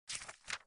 paper.mp3